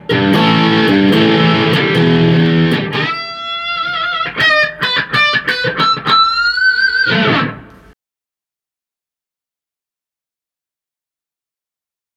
Distorsión PiMo
PiMo es como denominamos a nuestro pedal overdrive, diseñado para convertir la saturación de la señal de entrada en un sólido sonido grunge y rock, pero al ecualizar correctamente, puedes lograr un tono suave, limpio y moderado, para dejar sonar libremente la guitarra rítmica o poderosos solos, ideal para rock alternativo o pop moderno.
PiMoDemo.mp3